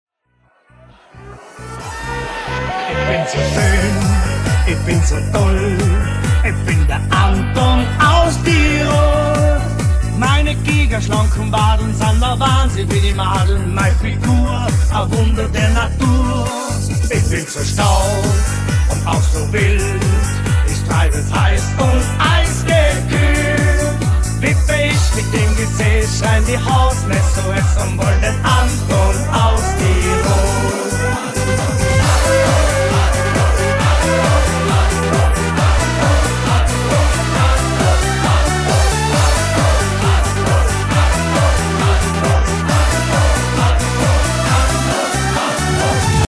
Танцевальная попса? Хендс Ап?